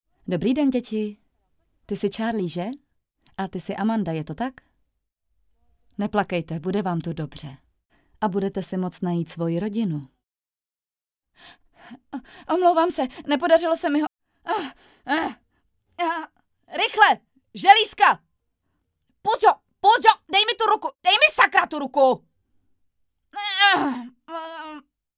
Dabing: